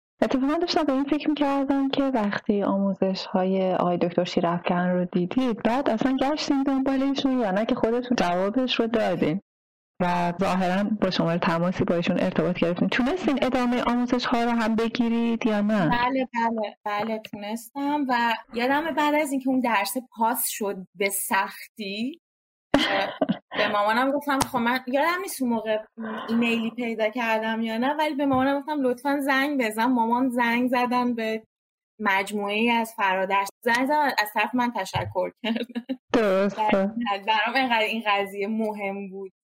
داستان موفقیت.مصاحبه